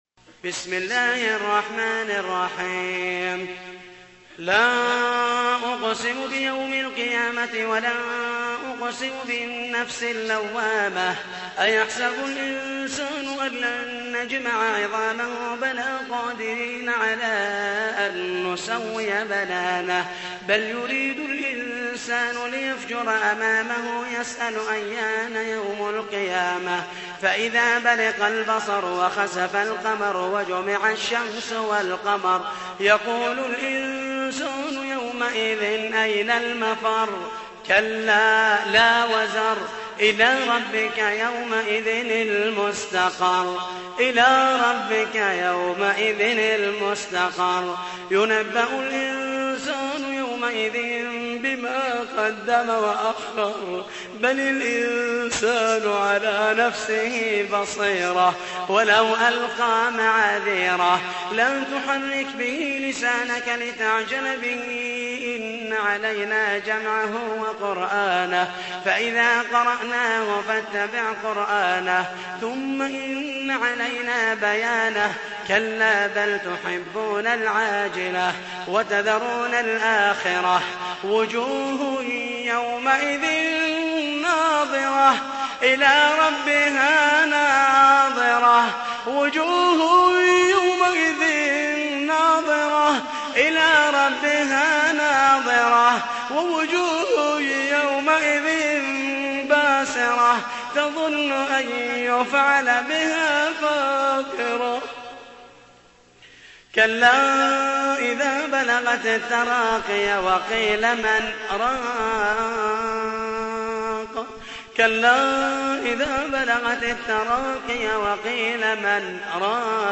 تحميل : 75. سورة القيامة / القارئ محمد المحيسني / القرآن الكريم / موقع يا حسين